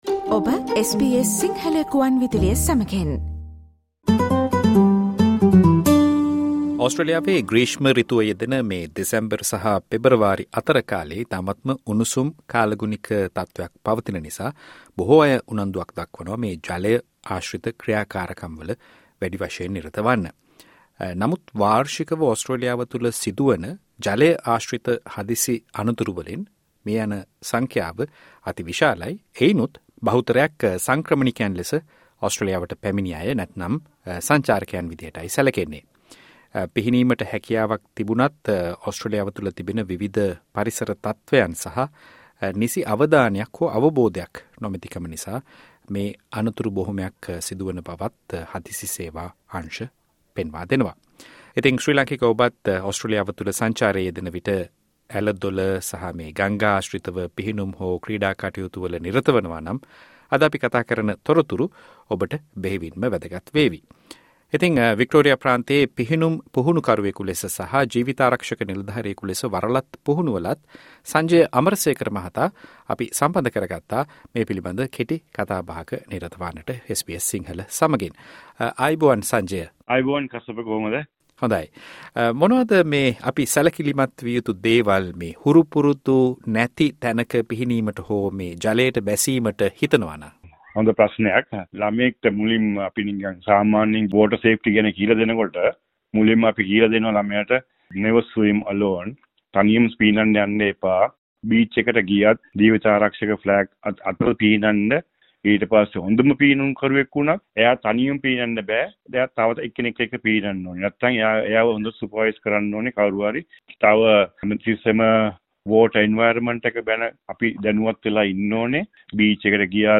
SBS සිංහල සේවය සිදු කල මේ කතා බහට